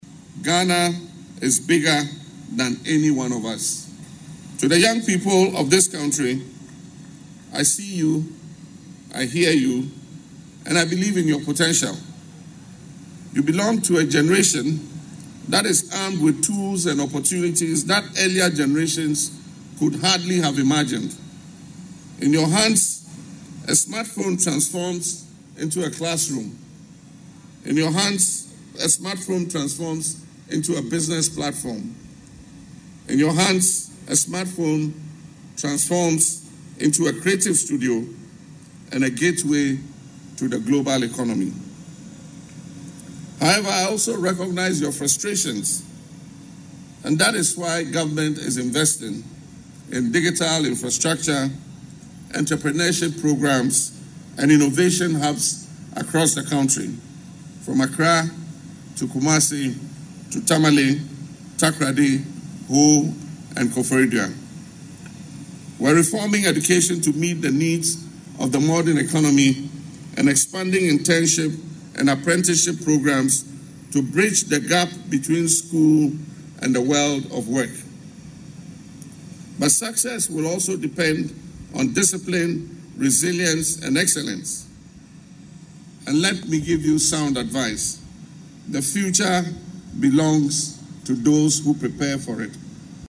Delivering the 69th Independence Day address, the President said Ghana’s young population possesses the skills and creativity needed to transform the country’s economic prospects.